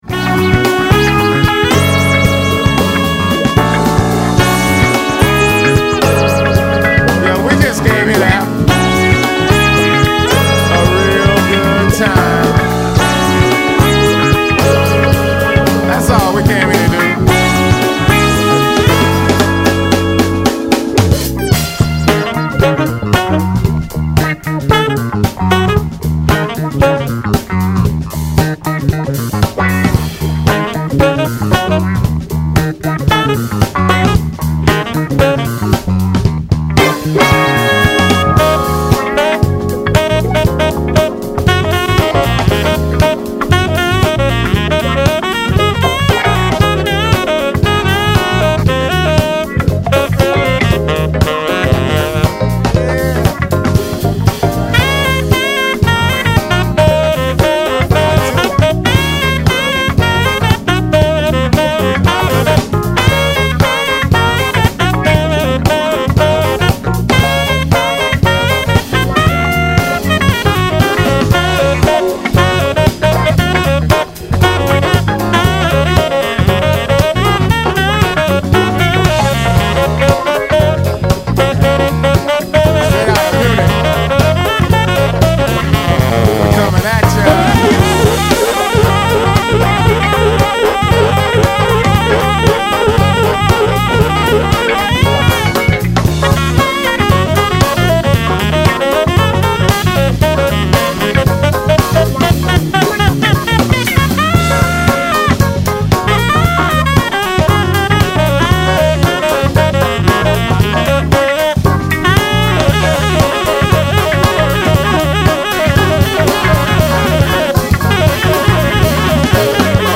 keys
drums
Rap
sax
guitar
bass